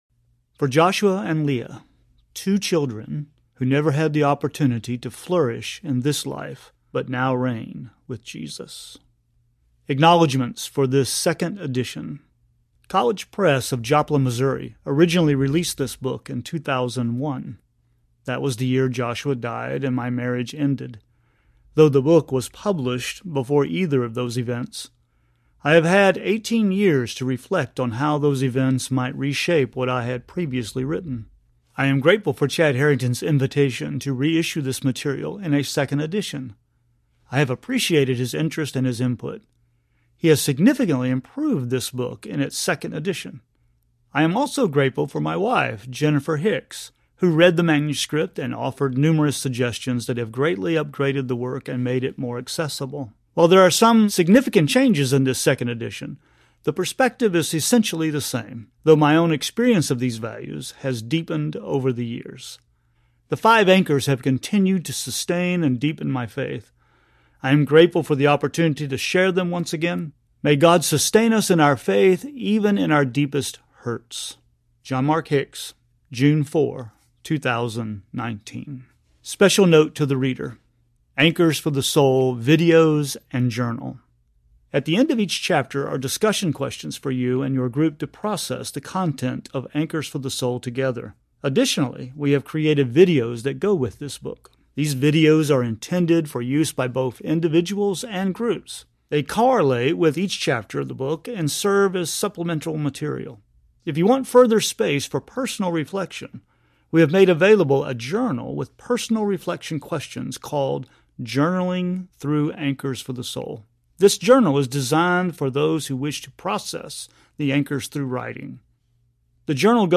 Anchors for the Soul Audiobook